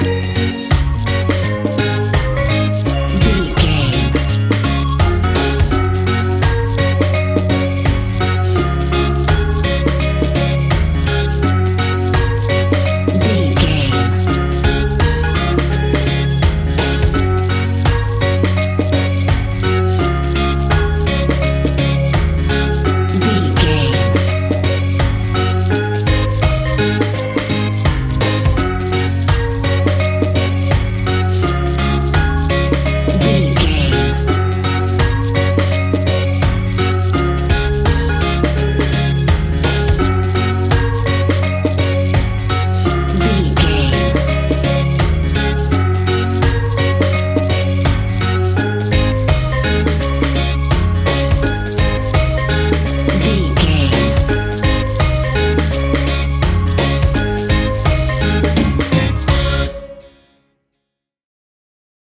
Uplifting
Ionian/Major
D♭
steelpan
calypso music
drums
percussion
bass
brass
guitar